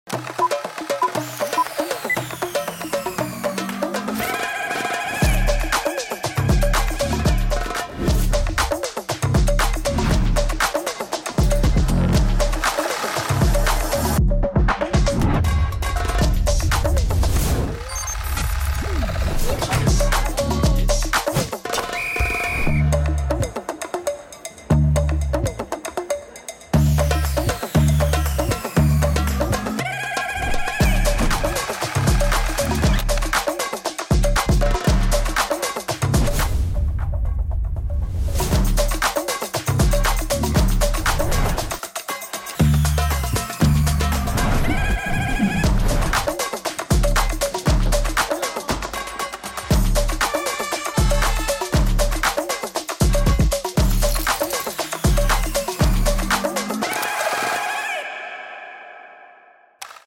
🌟 Put on your headphones and immerse yourself in the incredible sound experience that transports you back to Open Day.